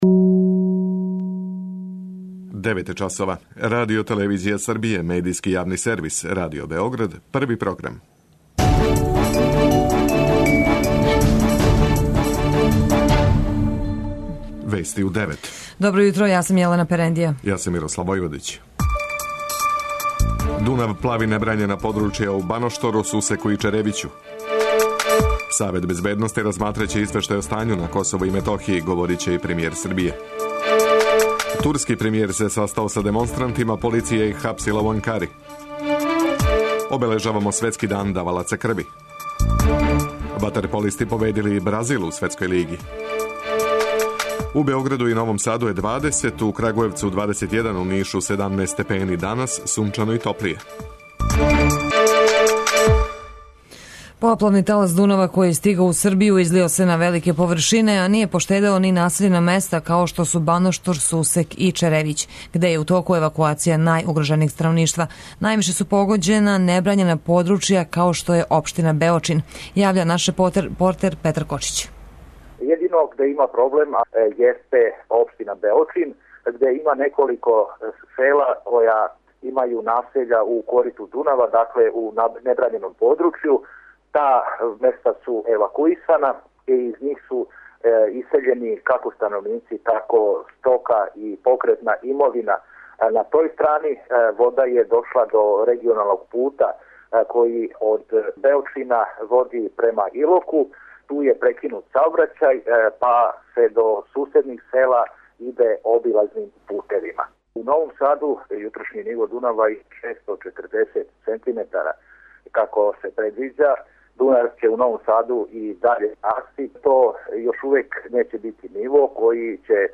преузми : 9.94 MB Вести у 9 Autor: разни аутори Преглед најважнијиx информација из земље из света.